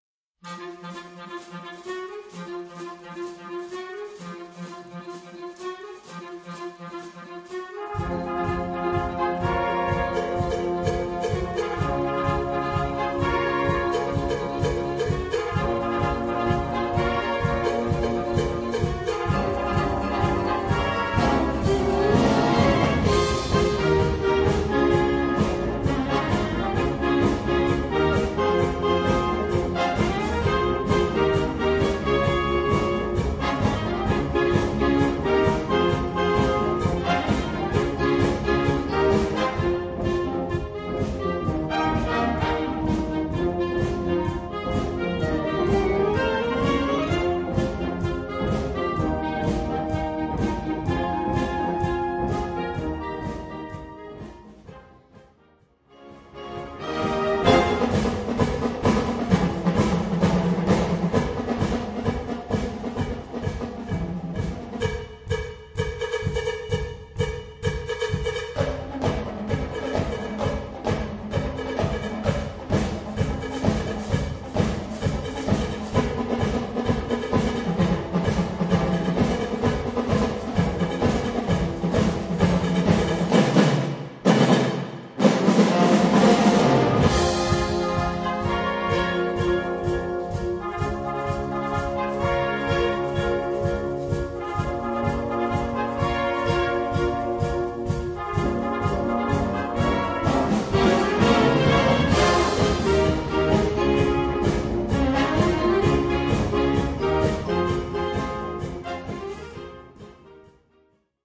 Besetzung: Blasorchester
ein unwiderstehliches Schlagzeugsolo